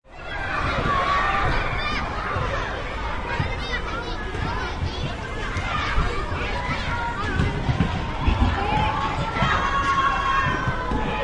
小孩子嬉戏环境音效免费音频素材下载